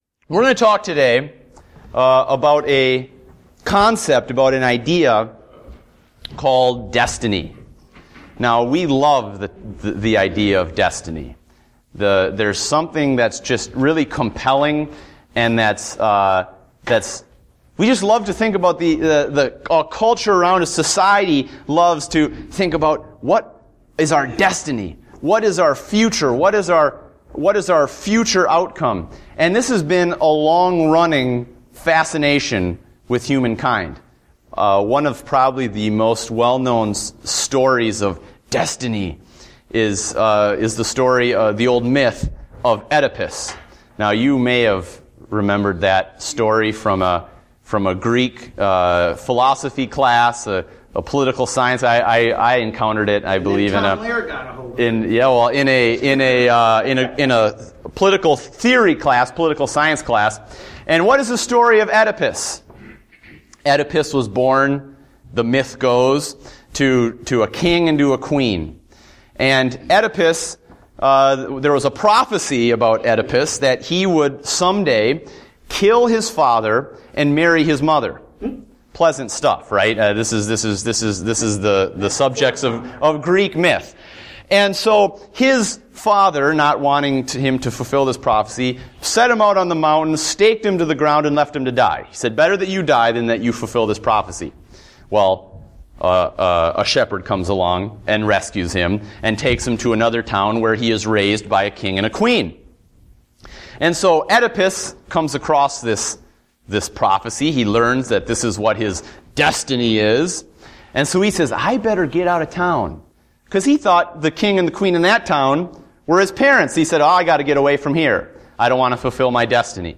Date: May 18, 2014 (Adult Sunday School)